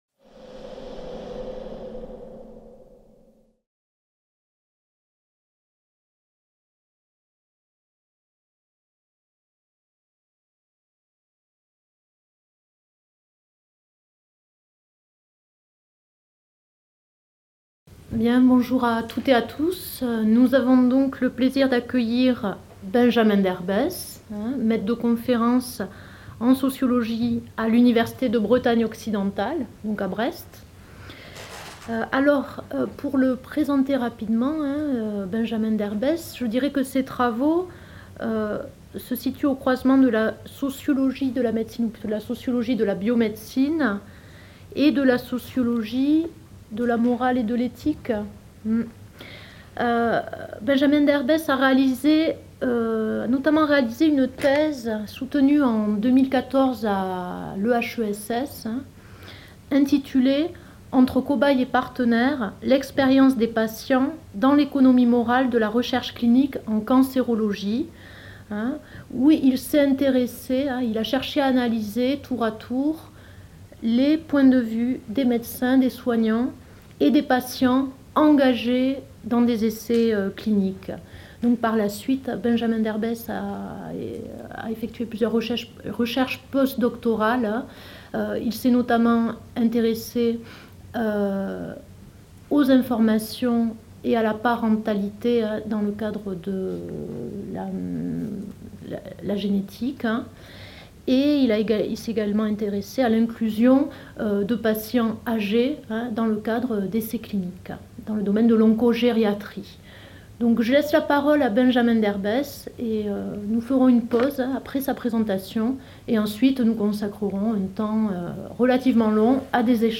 Amphi MRSH (derrière Amphi Tocqueville)